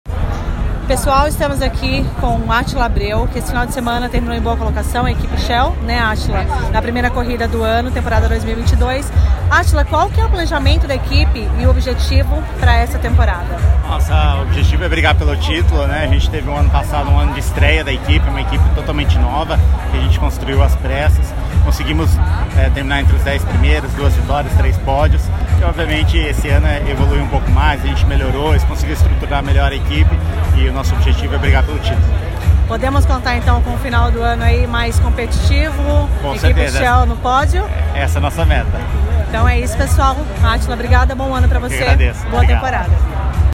No dia de Fevereiro, o Grupo Universal Automotive comemorou seu aniversário de 45 anos com uma super festa para seus parceiros e convidados no Autódromo de Interlagos, foi realizado o Motor Experience 2022.
Atila Abreu, piloto da Equipe Shel V-Power também falou sobre o final de semana na Stock Car e sobre os planejamentos para 2022;